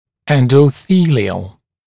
[ˌendə(u)ˈθiːlɪəl][ˌэндо(у)ˈси:лиэл]эндотелиальный, относящийся к эндотелию